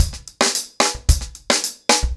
TimeToRun-110BPM.21.wav